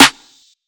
WEEKNDPARTY Snare.wav